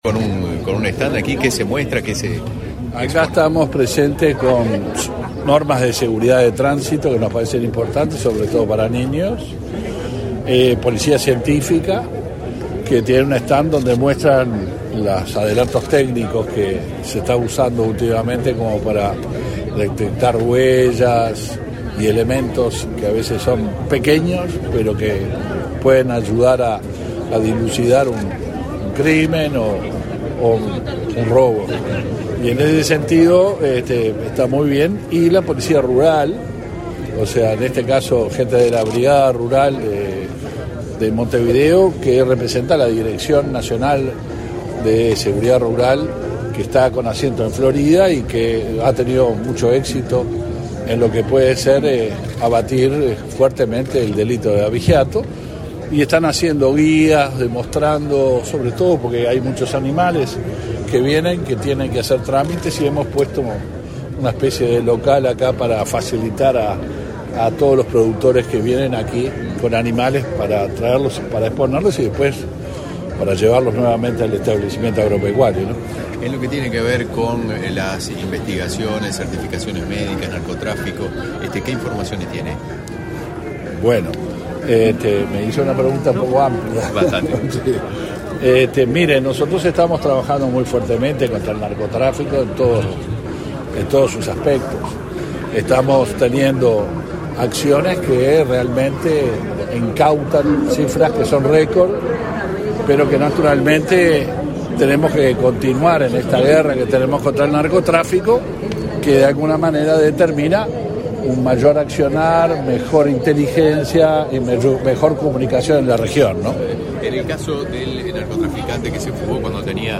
Declaraciones a la prensa del ministro del Interior, Luis Alberto Heber | Presidencia Uruguay
Declaraciones a la prensa del ministro del Interior, Luis Alberto Heber 08/09/2023 Compartir Facebook X Copiar enlace WhatsApp LinkedIn El ministro del Interior, Luis Alberto Heber, inauguró, este 8 de setiembre, el stand en la Expo Prado 2023. Tras el evento, el jerarca realizó declaraciones a la prensa.